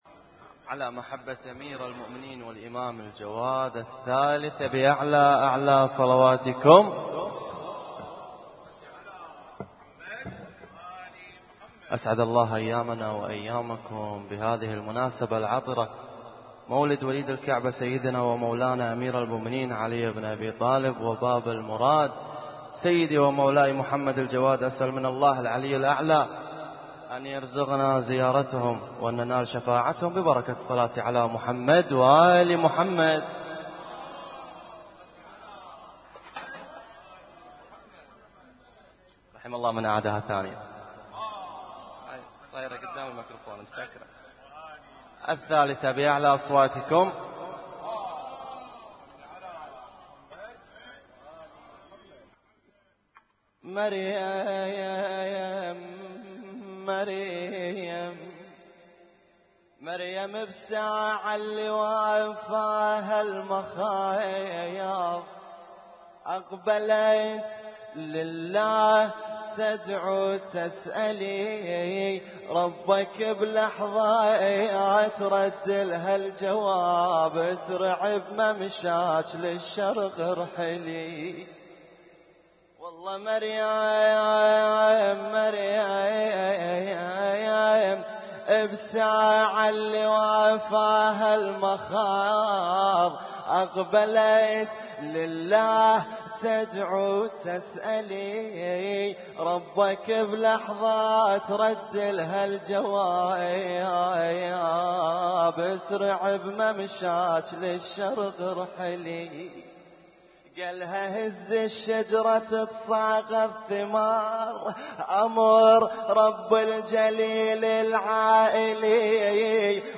اسم النشيد:: مولد الإمام علي والإمام الجوادعليهما لسلام - رجب 1441
القارئ: الرادود
اسم التصنيف: المـكتبة الصــوتيه >> المواليد >> المواليد 1441